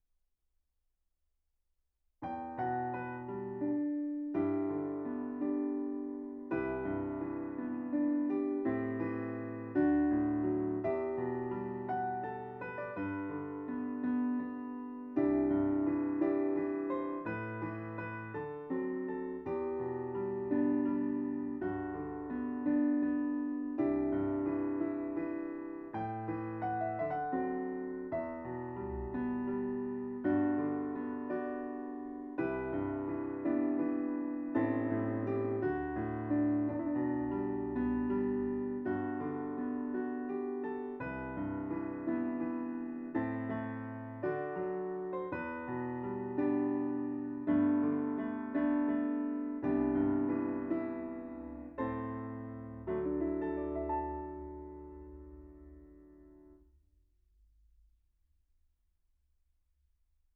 The idea being that this new track contains the string and body resonances of the real piano.
A spaced pair of AKG C414s aiming inside the top of the piano, close in on the strings, and then a speaker placed at the bottom of the piano aiming into the body (I used my Avantone Mix Cube for the job).I removed the lower panel of the piano so the speaker could play into the body (see photo)
Using a real piano to enhance the sound of a sampled piano
Here’s the re-amped track:
movin-on-piano-reamped.wav